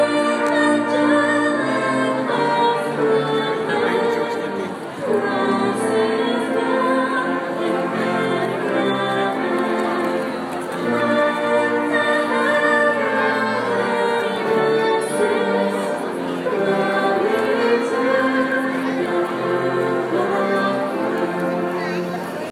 Yesterday, as we had just arrived in Brisbane, we went for an evening walk, and found a band playing and singing Christmas carols in the square (and it wasn’t even Advent then!) For an authentic ‘in the crowd’ experience, just listen to the audio below!
CAROLS IN BRISBANE!
brisbanecarols.m4a